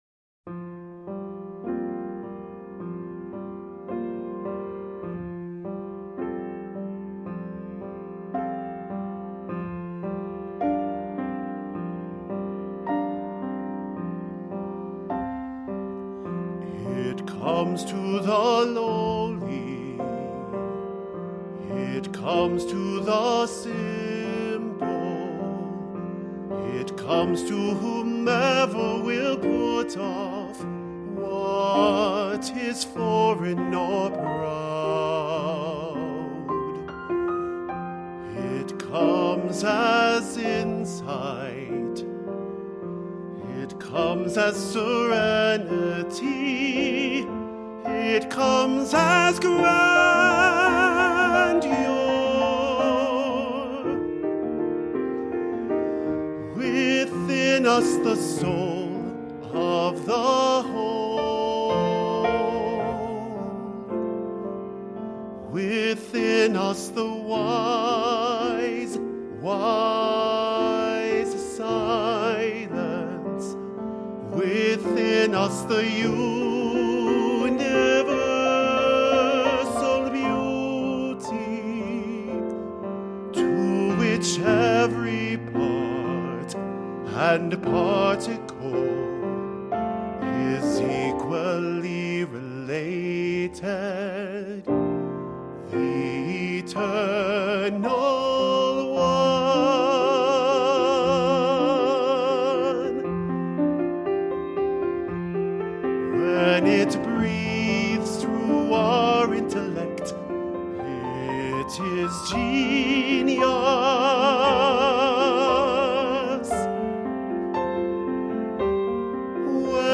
baritone
piano.
Medium voice, piano